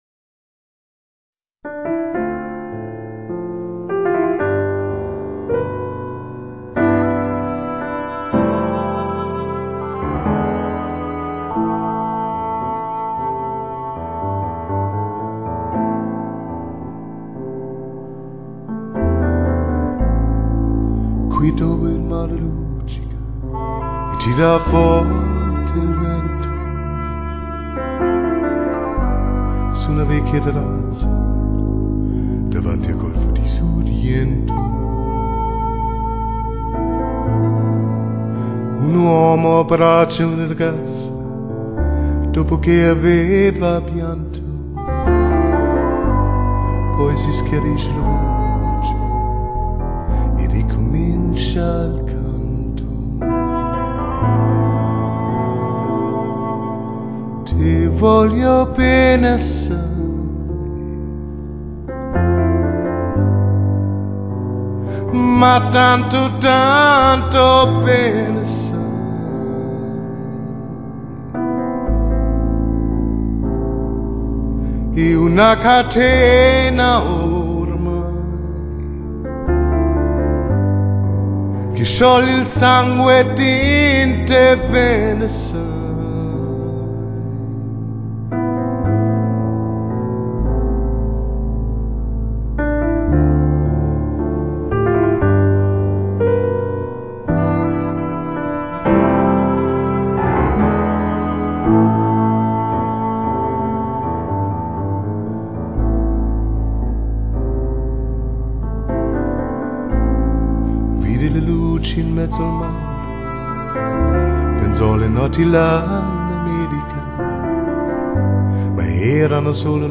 (低音質wma / 64k)